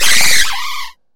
Cri de Pomdramour dans Pokémon HOME.